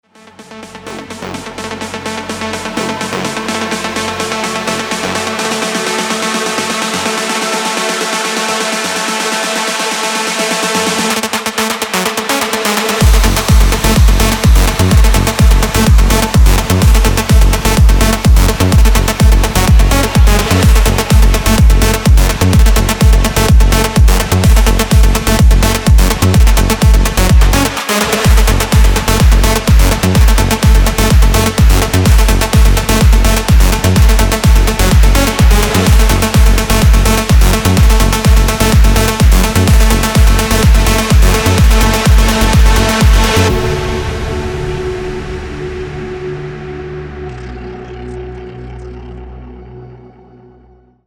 Massively inspired by the genre’s pioneers David Guetta and MORTEN, Nocturne features 62 Serum presets expertly crafted for Future Rave productions.
Inside you will find 15 punchy bass presets, 25 tights leads and synths, 7 pad presets, 5 plucks, and 9 effects.
Demo